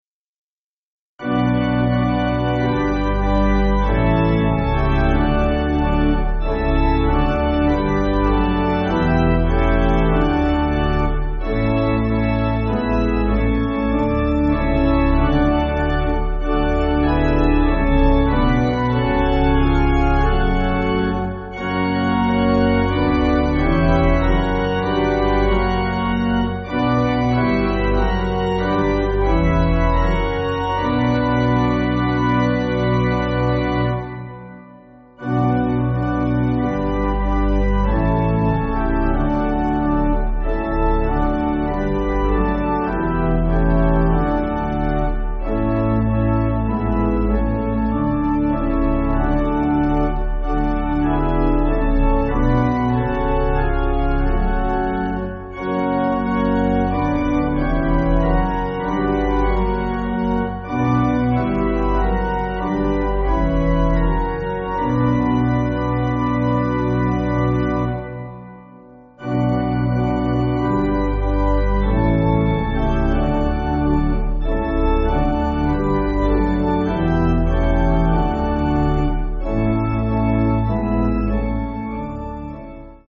Organ
(CM)   4/Bm